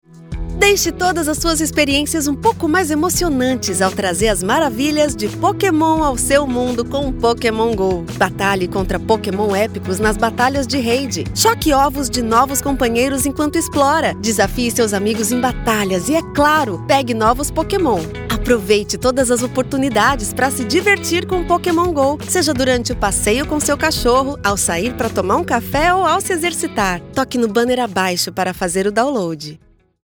Female
Approachable, Assured, Bright, Cheeky, Conversational, Corporate, Deep, Friendly, Natural, Posh, Smooth, Warm, Young
My accent is considered neutral, with a soft memory of São Paulo prosody.
Warm, youthful, and highly professional, my voice delivers a natural and conversational performance with a neutral accent.
Microphone: AKG P220